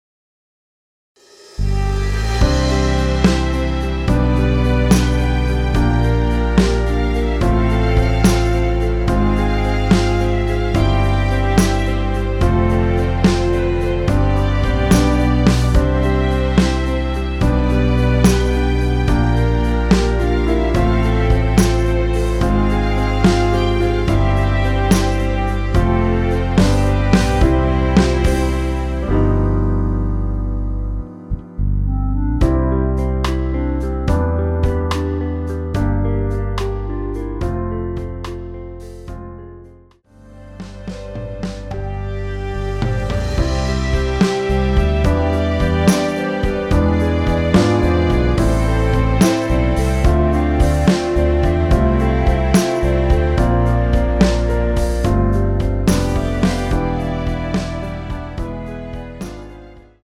원키 멜로디 포함된 MR입니다.(미리듣기 확인)
앞부분30초, 뒷부분30초씩 편집해서 올려 드리고 있습니다.